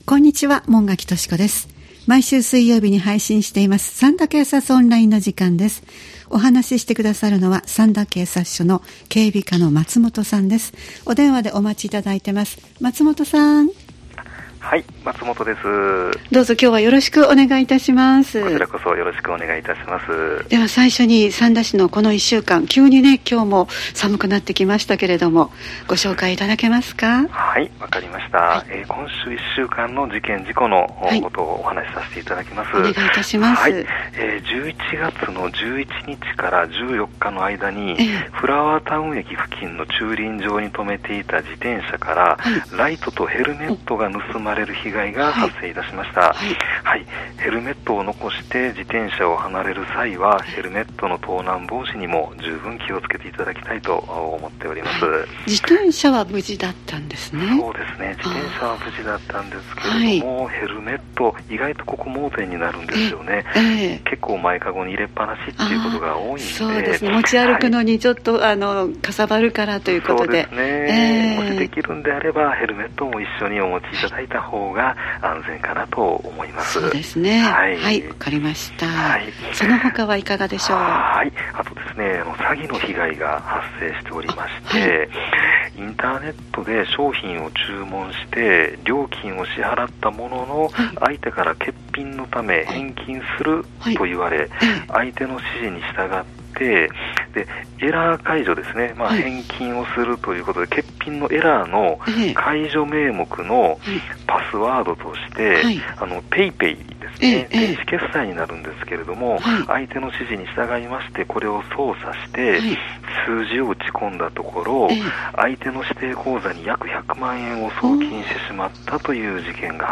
三田警察署に電話を繋ぎ、三田で起きた事件や事故、防犯情報、警察からのお知らせなどをお聞きしています（再生ボタン▶を押すと番組が始まります）